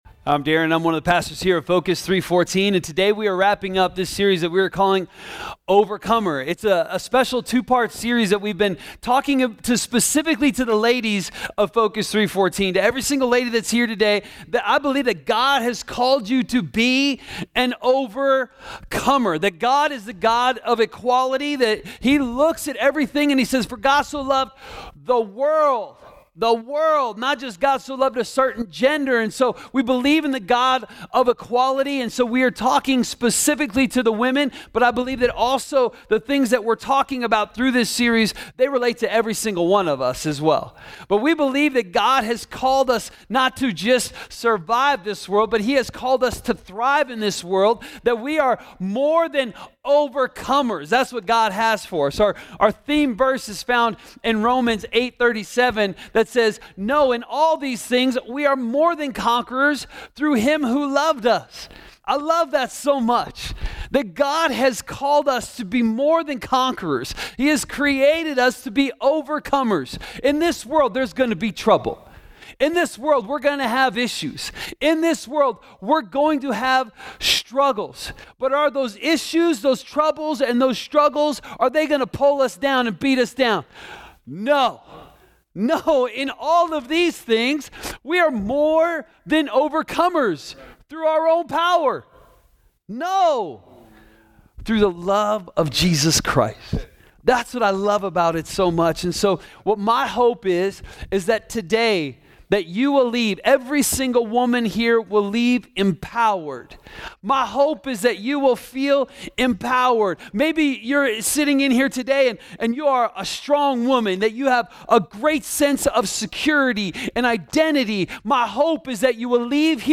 A message from the series "Overcomer."